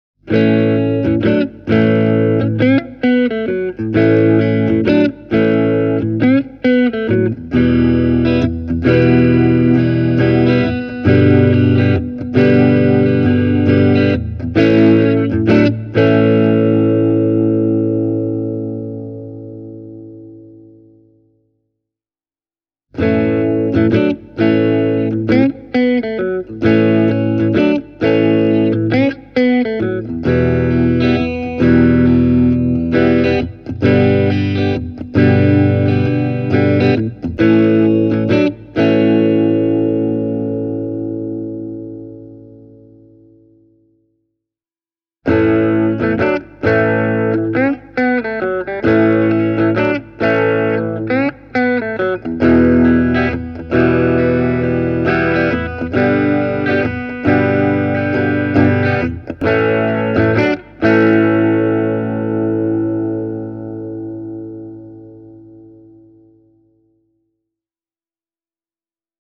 Täytyy kyllä sanoa, että Tokain omat PAF-tyyliset humbuckerit kuulostavat loistavilta! Lähtötaso on hyvin maltillinen ja perussoundi on mukavan avoin. Kaulamikki on lämmin, mutta erotteleva, ja valitsimen keskiasennossa soundi on ilmava. Tallahumbucker taas soi hyvin tuoreella äänellä, mutta ei ”marise” häiritsevästi keskialueella.